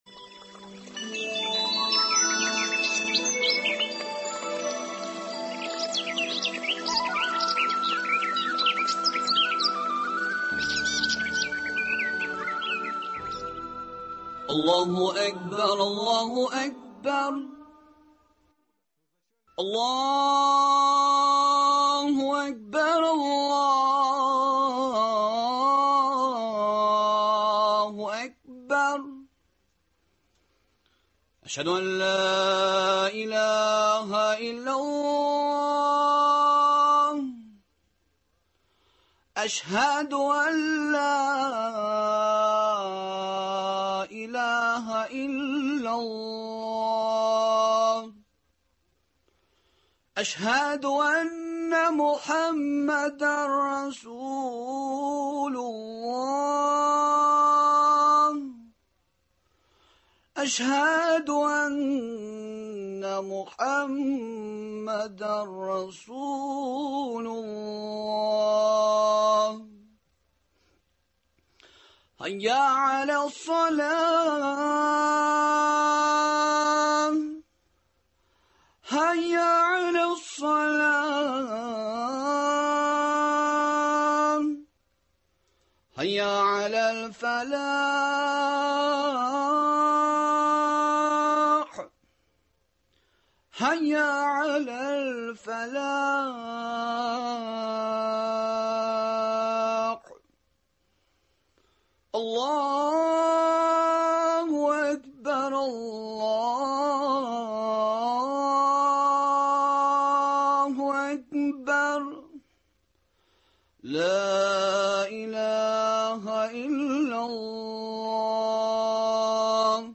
Бу сорауларга җавапны студия кунагы